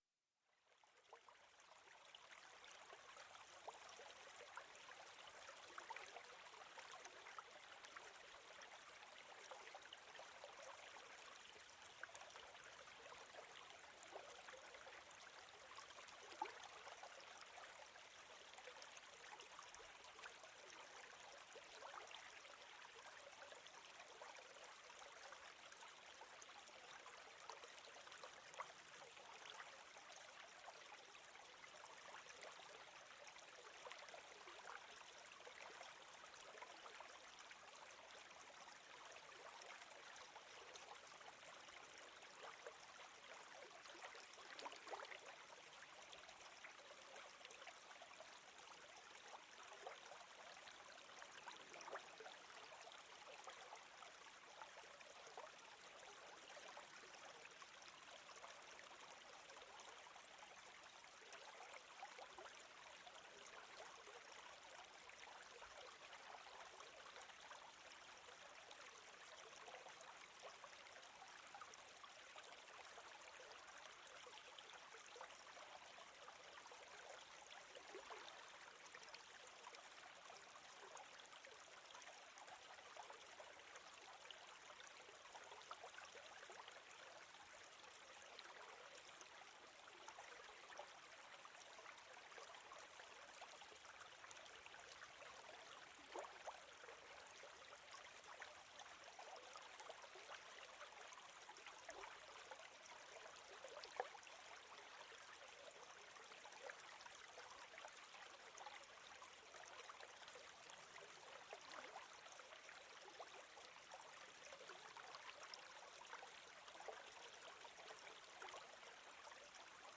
FREUDE & LEBENSKRAFT: Morgenglanz-Energie mit Naturklängen voller Power